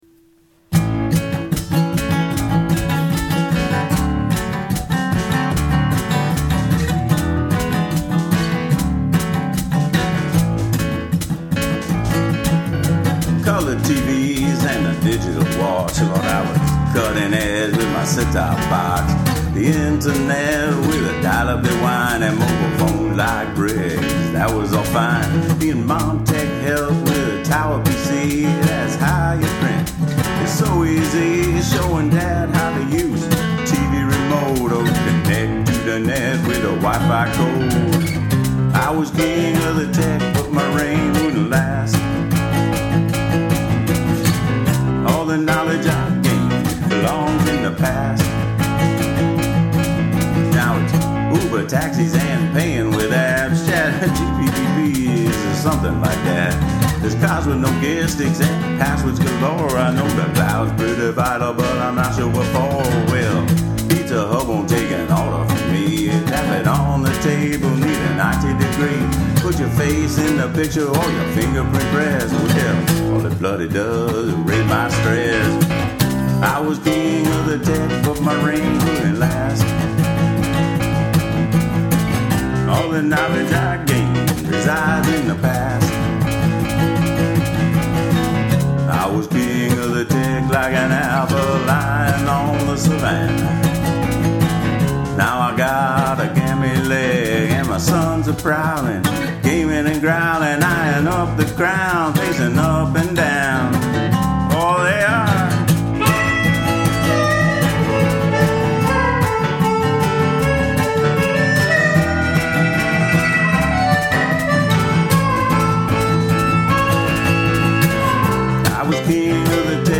drums
piano
guitar/vocal/harmonica
Great lazy bluesy feel!